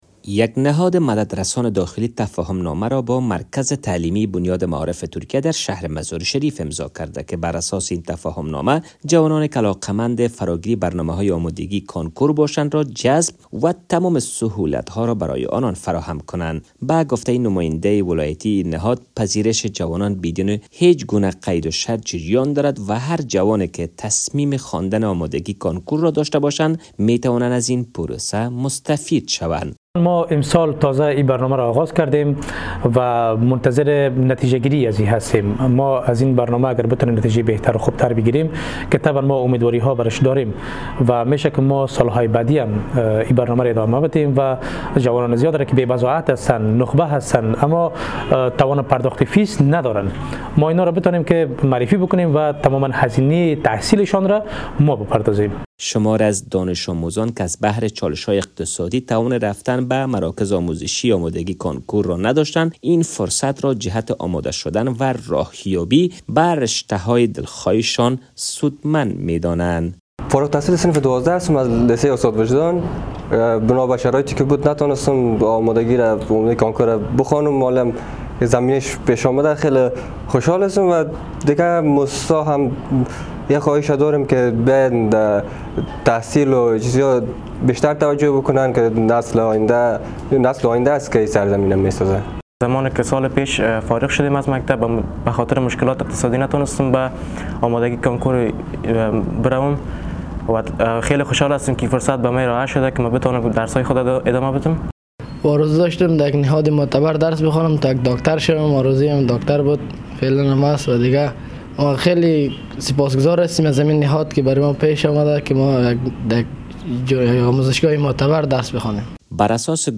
خبر / افغانستان